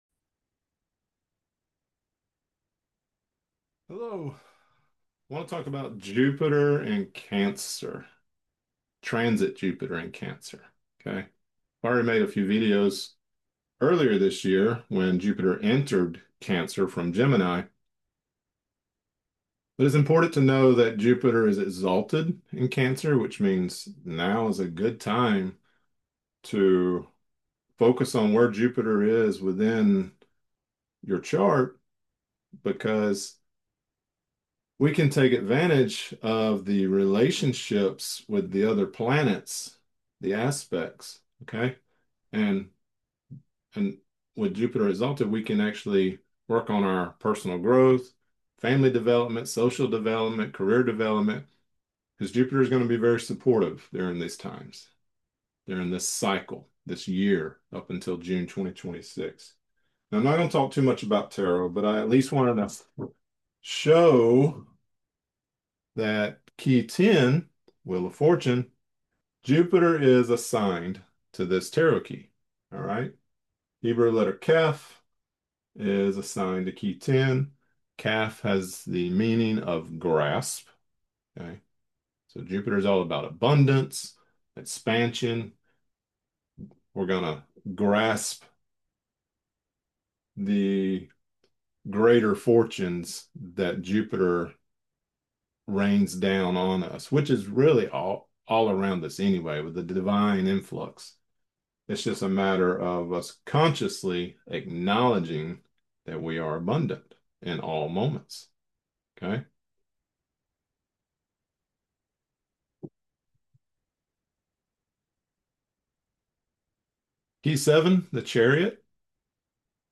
Jupiter brings forth the possibilities to go beyond what we consider limits. Lecture Created Transcript Blockchain Lecture Transcript 10/16/2025 Lecture slides 10/16/2025 Lecture audio only 10/16/2025 Watch lecture: View 2025 Lectures View All Lectures